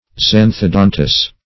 Search Result for " xanthodontous" : The Collaborative International Dictionary of English v.0.48: Xanthodontous \Xan`tho*don"tous\, a. [Xantho- + Gr.
xanthodontous.mp3